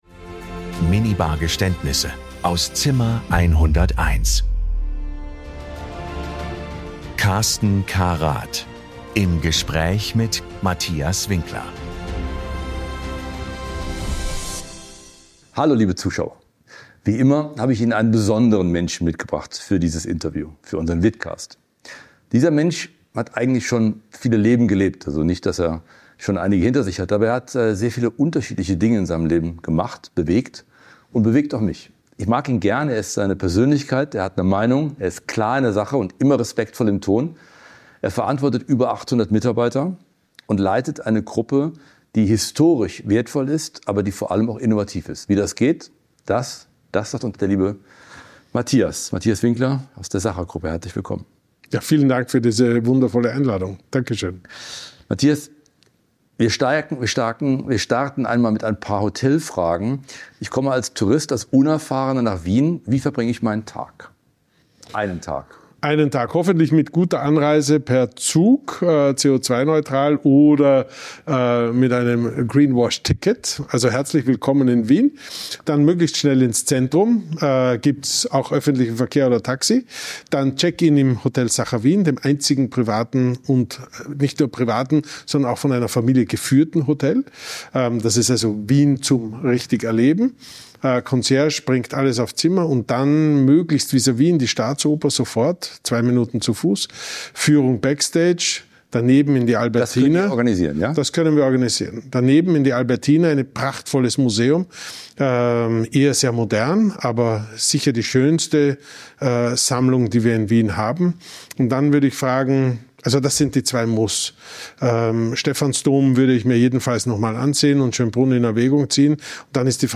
Es wird gelacht, reflektiert und manchmal auch gestanden. Hier wird Hospitality persönlich.